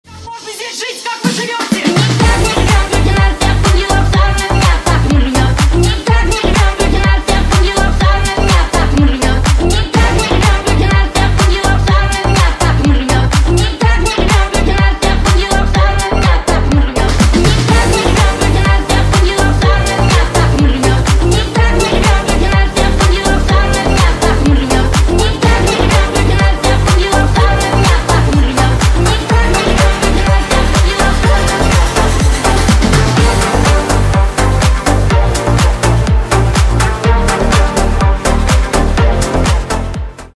Весёлые Рингтоны
Рингтоны Ремиксы